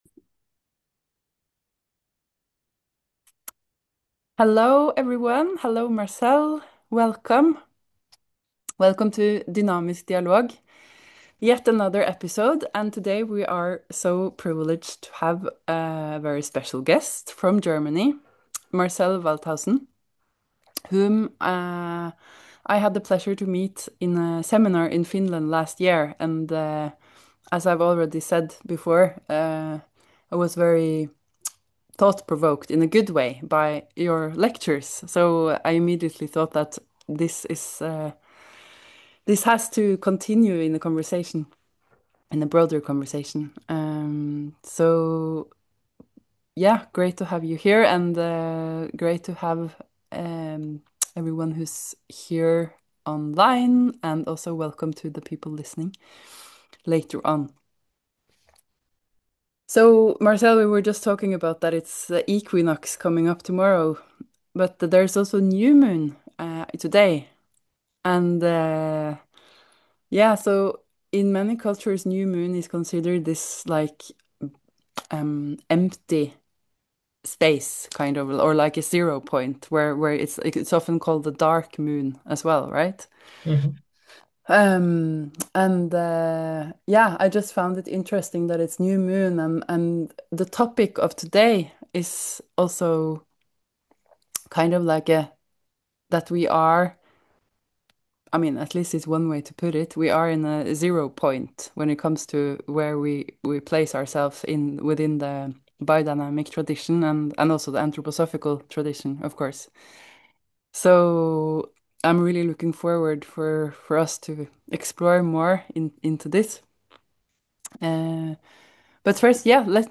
Samtalen foregår på zoom og i etterkant åpner vi opp for spørsmål fra lytterne.